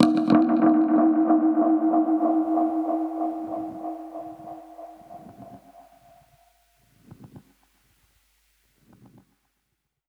Index of /musicradar/dub-percussion-samples/95bpm
DPFX_PercHit_A_95-08.wav